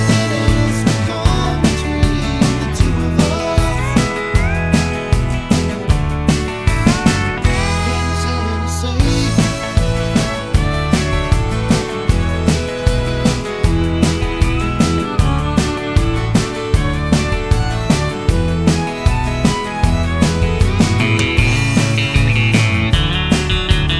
no Backing Vocals Country (Male) 2:42 Buy £1.50